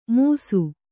♪ mūsu